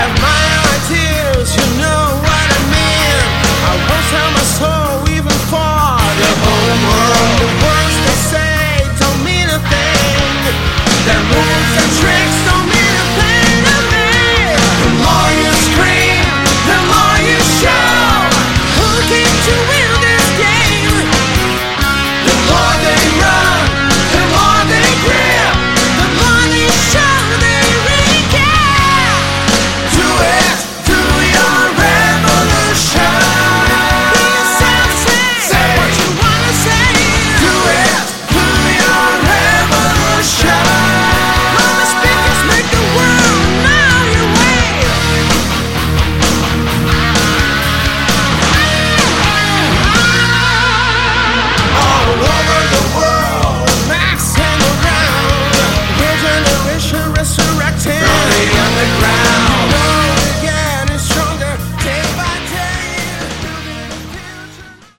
Category: Melodic Hard Rock
guitar, vocals
bass
keyboards
drums